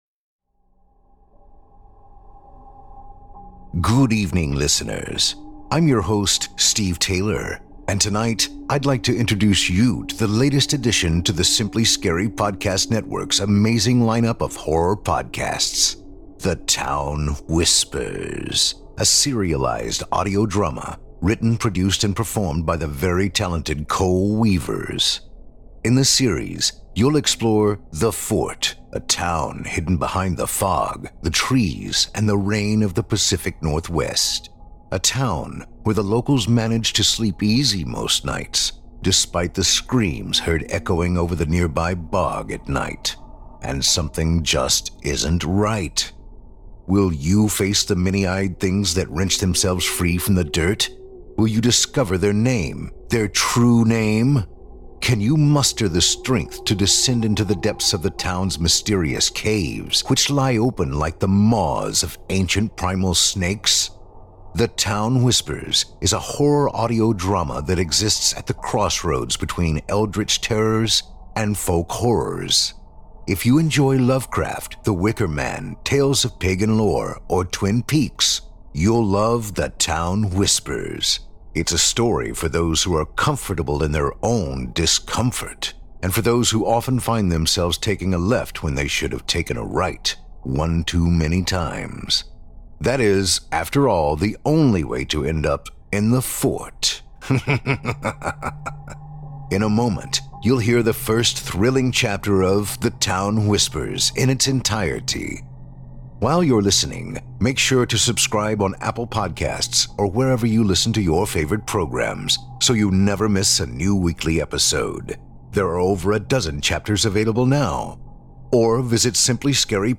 The Town Whispers is a horror audio drama that exists at the crossroads between Eldritch terrors, and folk horrors.